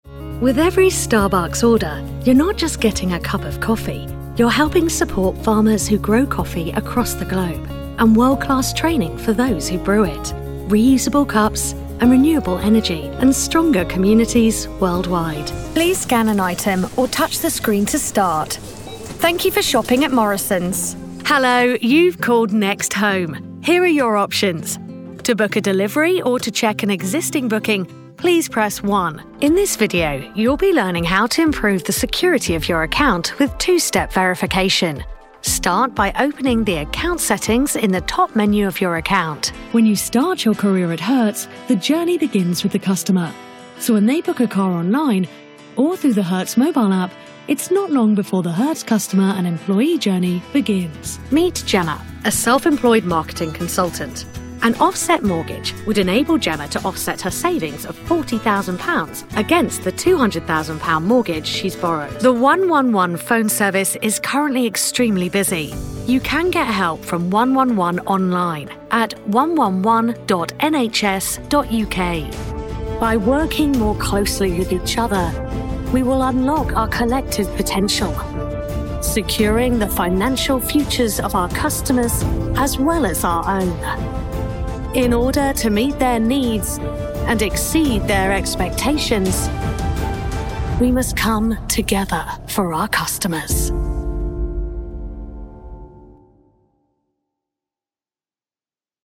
Natural, Accesible, Versátil, Maduro, Suave
Corporativo
Para describir rápidamente su voz: es cálida, natural, animada, fresca, atrevida y comercial.